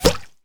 bullet_impact_mud_06.wav